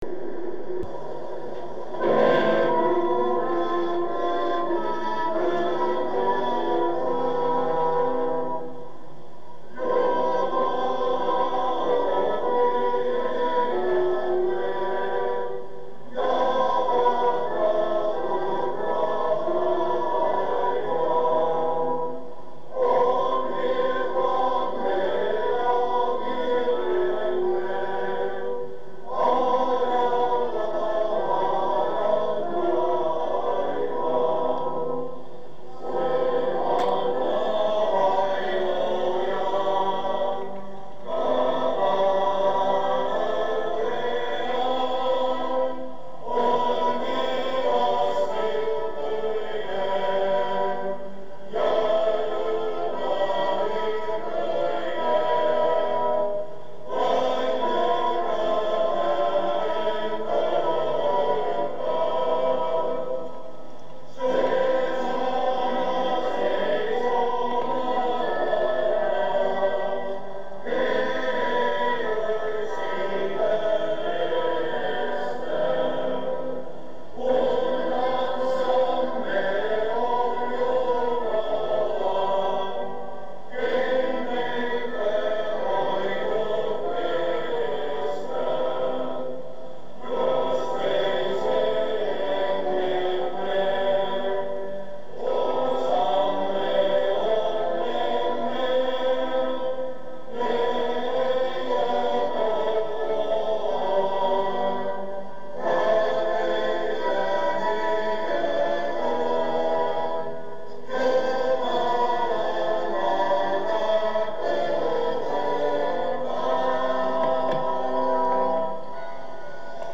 Kaupungin Vanhalle Suurtorille kerääntyy tuhansia ihmisiä kuuntelemaan joulurauhan julistusta kello 12.
Tuomiokirkon kellon lyötyä 12 kajahtaa virsi "Jumala ompi linnamme", jonka jälkeen joulurauha julistetaan kaupungin asukkaille Brinkkalan talon parvekkeelta pergamenttirullalta.
Come nel passato anche oggi la Pace di Natale viene solennemente proclamata a mezzogiorno della Vigilia di Natale.